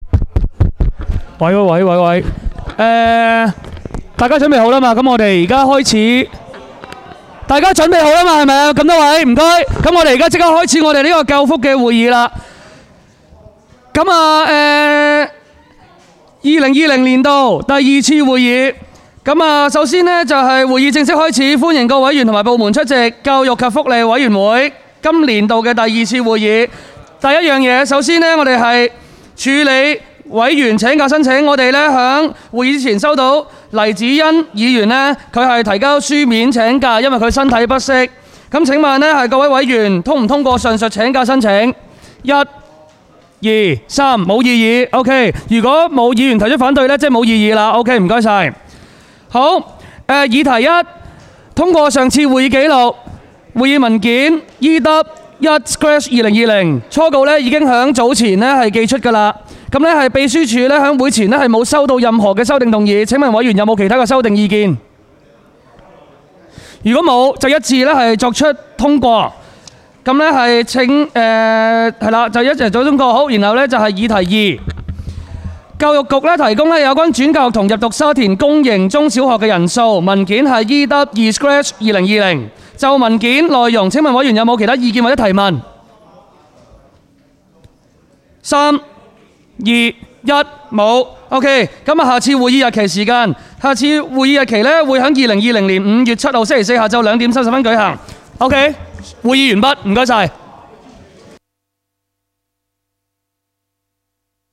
委员会会议的录音记录
教育及福利委员会第二次会议 日期: 2020-03-12 (星期四) 时间: 下午2时30分 地点: 区议会会议室 议程 讨论时间 I 教育局提供有关转介学童入读沙田区公营中小学人数 00:01:44 全部展开 全部收回 议程:I 教育局提供有关转介学童入读沙田区公营中小学人数 讨论时间: 00:01:44 前一页 返回页首 如欲参阅以上文件所载档案较大的附件或受版权保护的附件，请向 区议会秘书处 或有关版权持有人（按情况）查询。